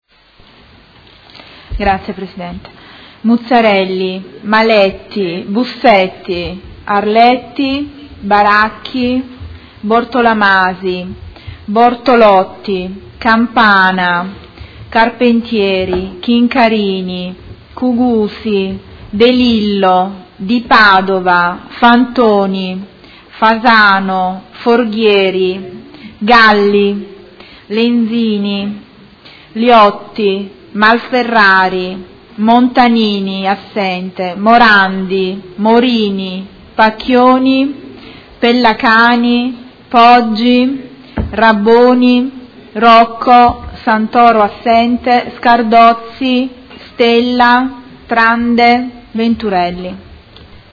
Seduta del 16/03/2017. Appello